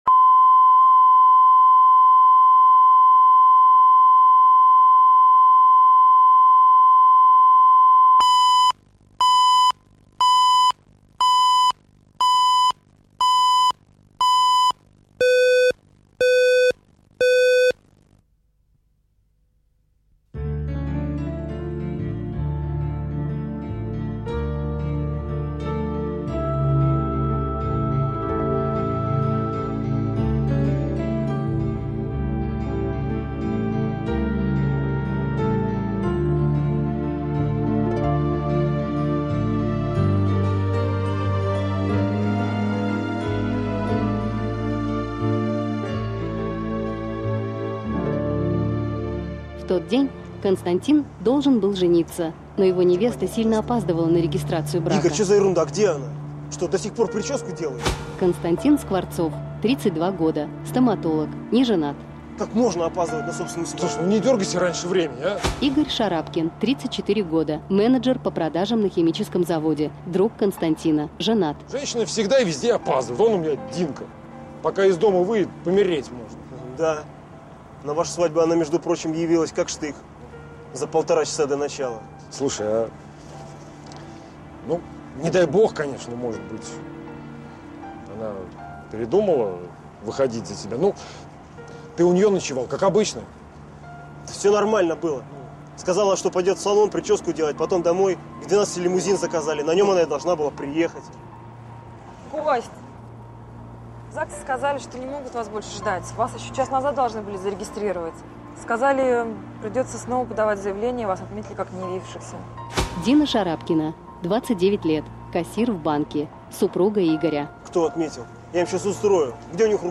Аудиокнига Сбежавшая невеста | Библиотека аудиокниг
Прослушать и бесплатно скачать фрагмент аудиокниги